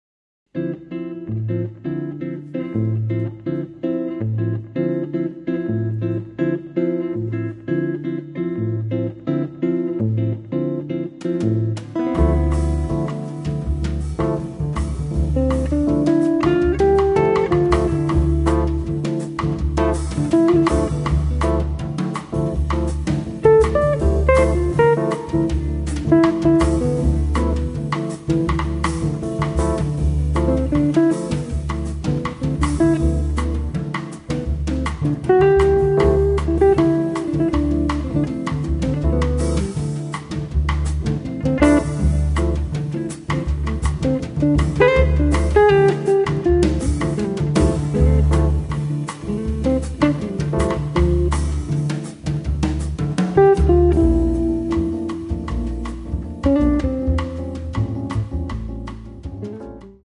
chitarra
organo e piano elettrico
contrabbasso
batteria